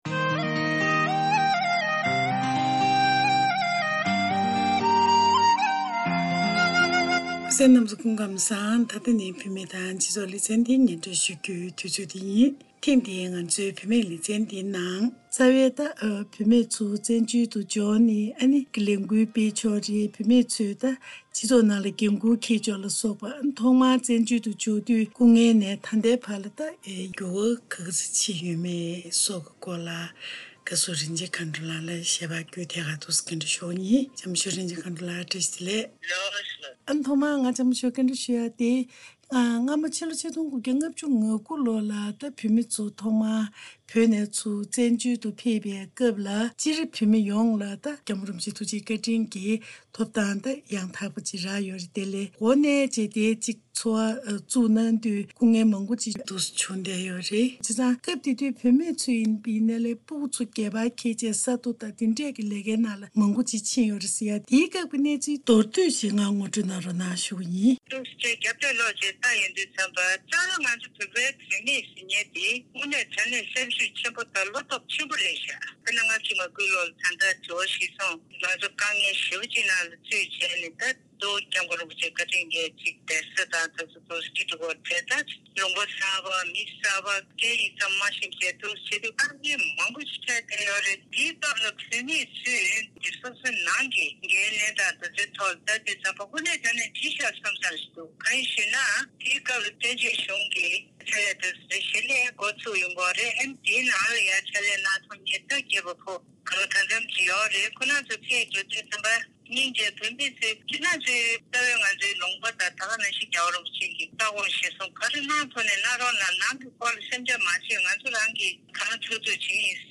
བཀའ་འདྲི་ཞུས་པའི་ལས་རིམ་ཞིག